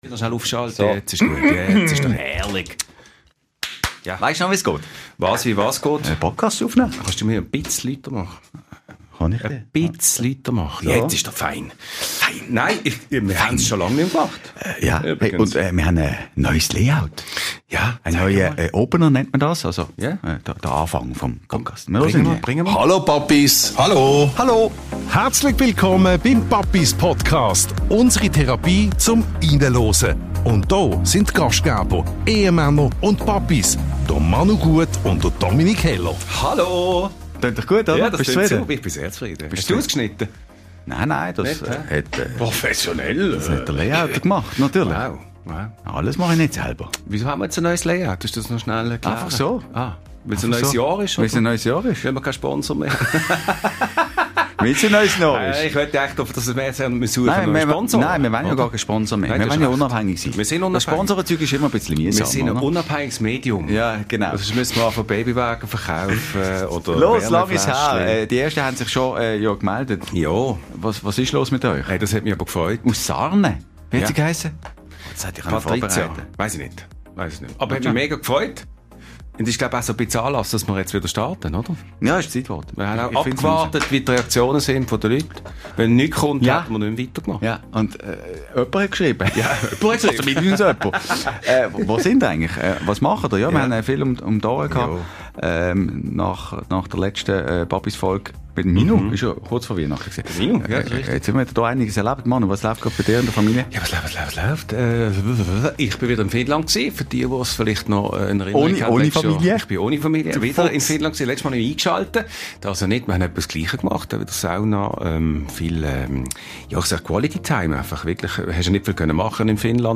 Diese Folge ist wie ein Familienfrühstück am Sonntag: laut, chaotisch, völlig unstrukturiert – aber super lecker.